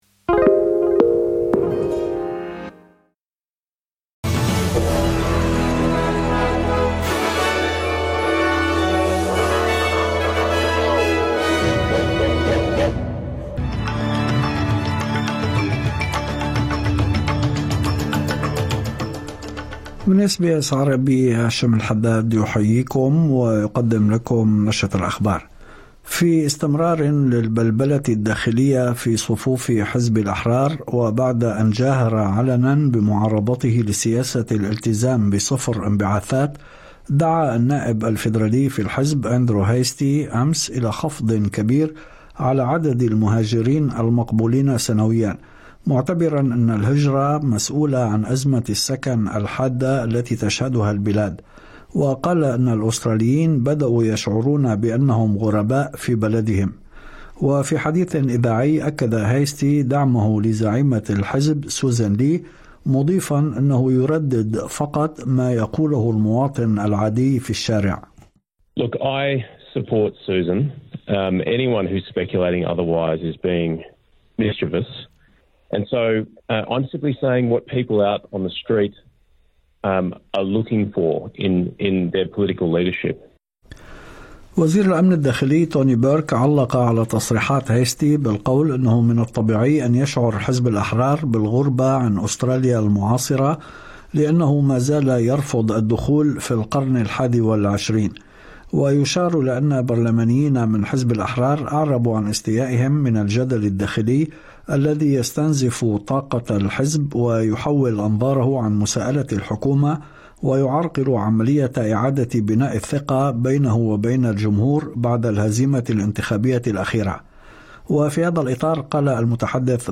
نشرة أخبار الظهيرة 26/09/2025